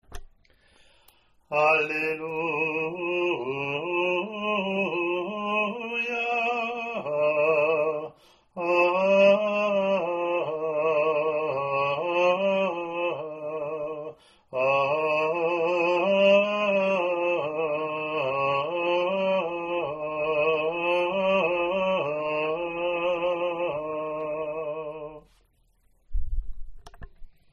Responsorial or Alleluia:  (cantor sings once, schola repeats, cantor sings verses, schola leads assembly in response between verses).
first Alleluia (after first reading)
ea02-alleluia1-gm.mp3